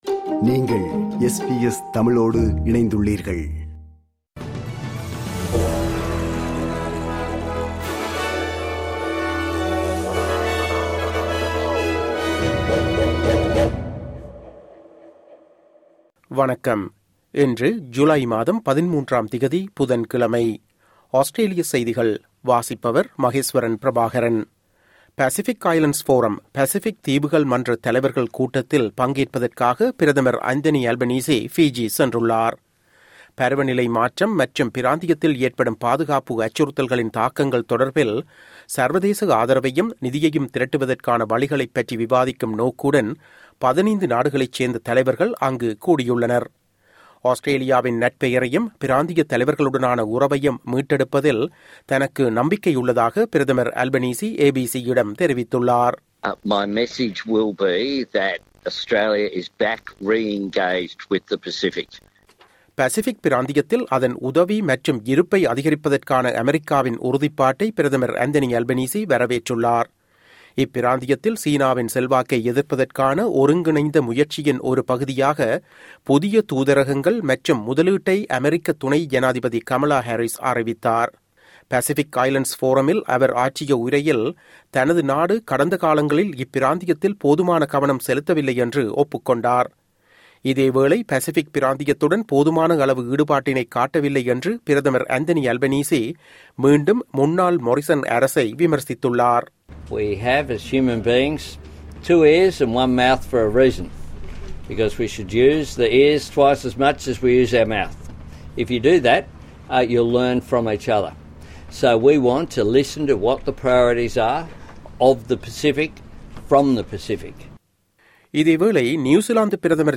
Australian news bulletin for Wednesday 13 July 2022.